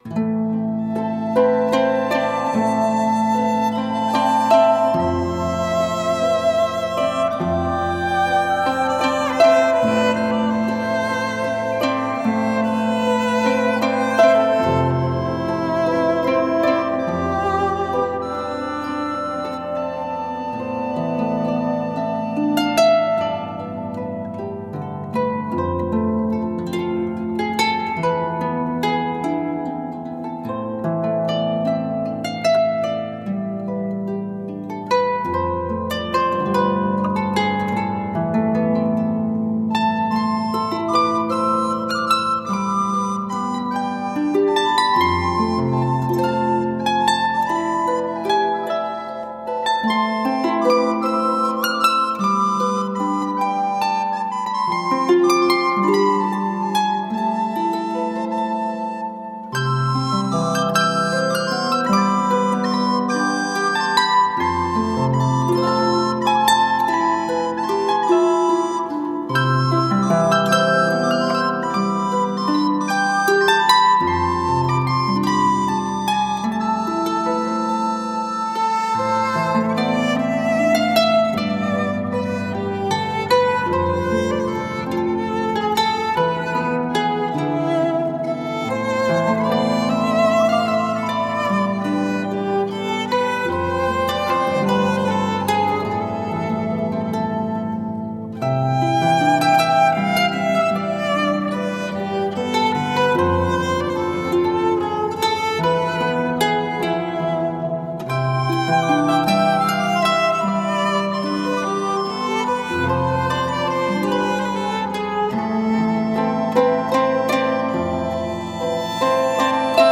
Timeless and enchanting folk music for the soul.
Tagged as: World, Folk, Christmas, Harp